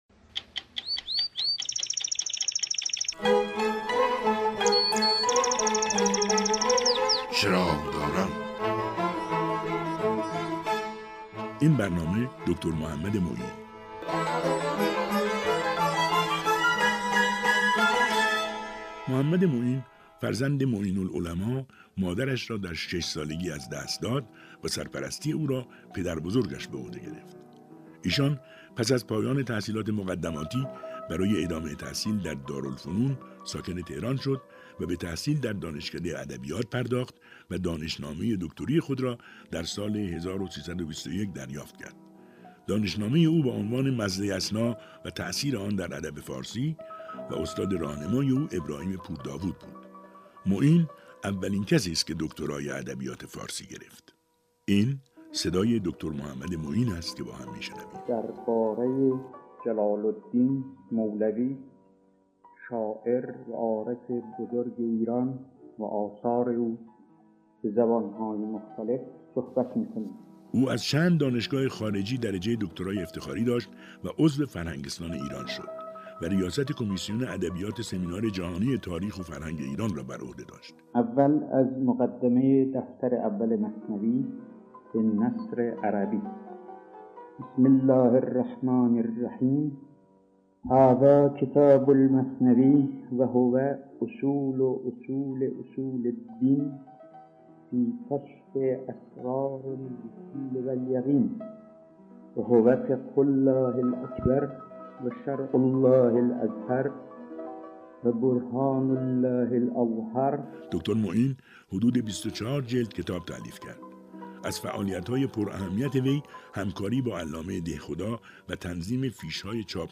دیدم خودنویس از دستش افتاد... + صدای معین